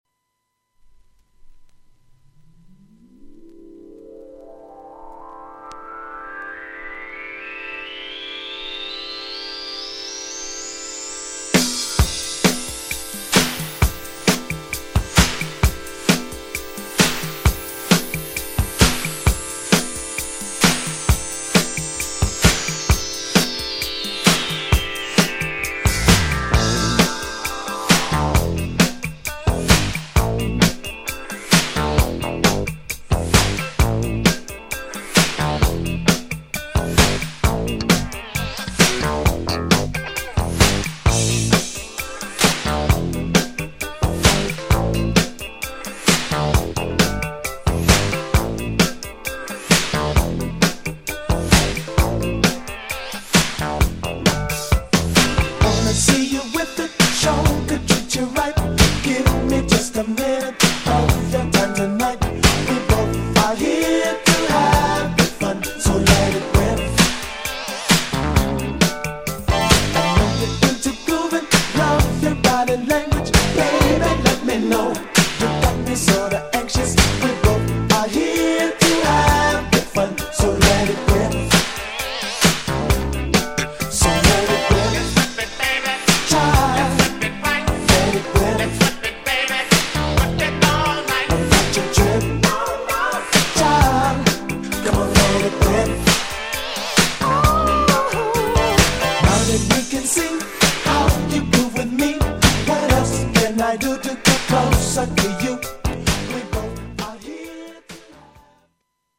GENRE Dance Classic
BPM 126〜130BPM